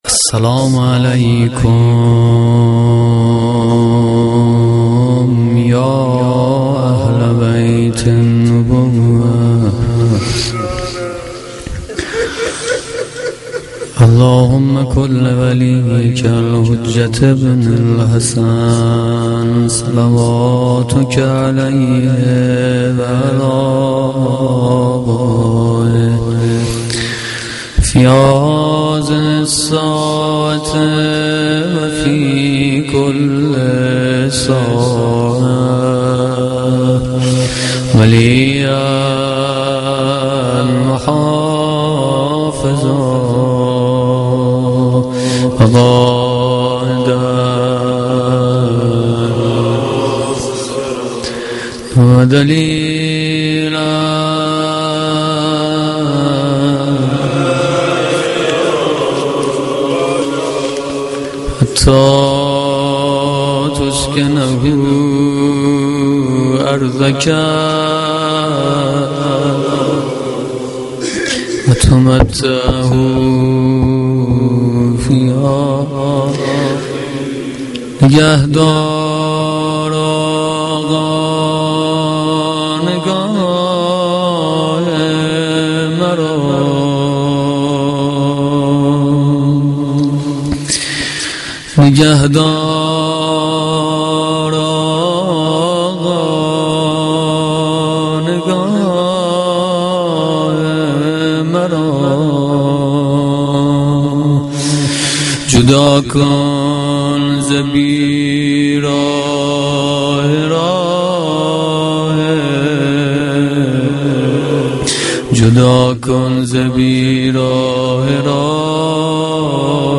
مداحی
Shab-5-Moharam-2.mp3